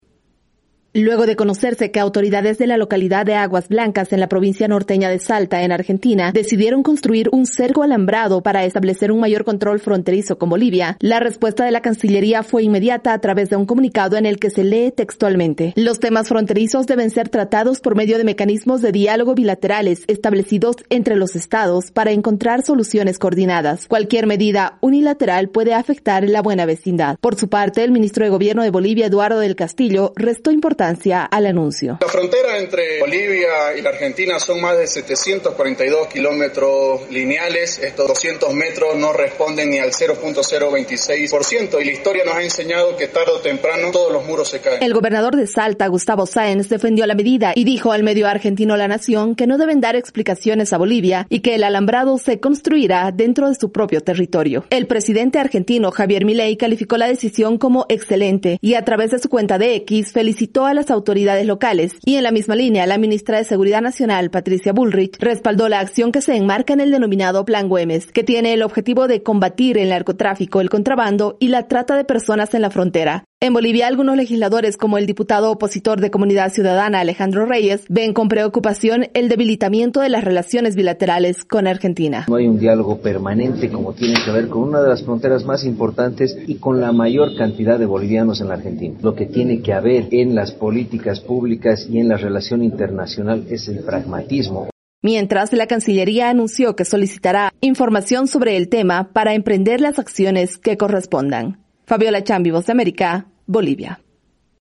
Varias reacciones se generaron en Bolivia luego del anuncio del gobierno argentino de levantar un cerco alambrado para un mayor control fronterizo. Desde Bolivia informa la corresponsal de la Voz de América